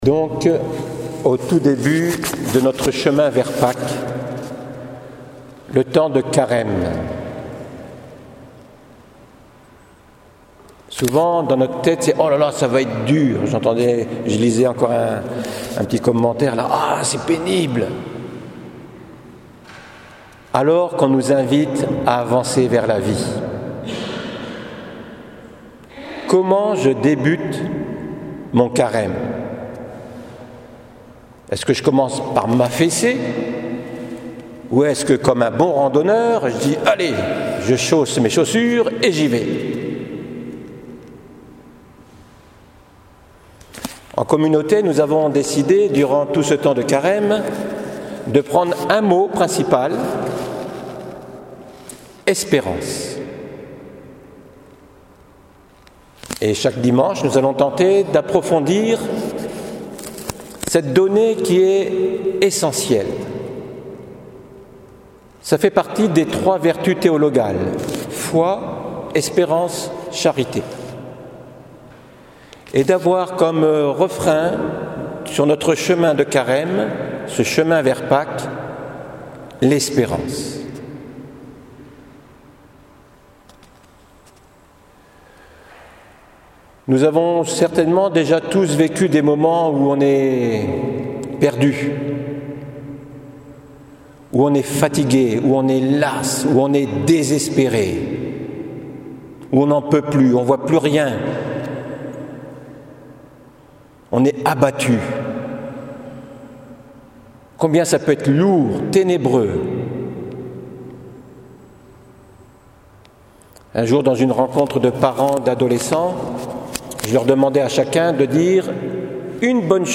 homélie du dimanche l’espérance au delà de l’épreuve